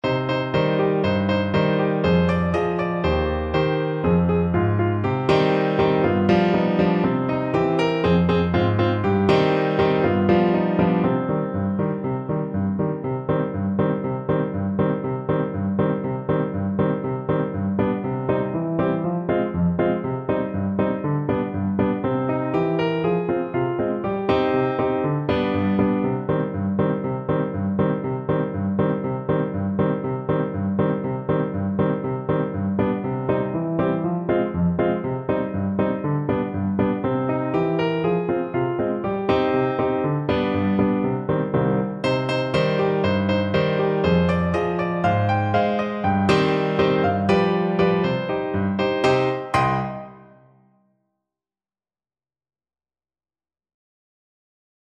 Play (or use space bar on your keyboard) Pause Music Playalong - Piano Accompaniment Playalong Band Accompaniment not yet available transpose reset tempo print settings full screen
2/4 (View more 2/4 Music)
C minor (Sounding Pitch) D minor (Clarinet in Bb) (View more C minor Music for Clarinet )
Allegro (View more music marked Allegro)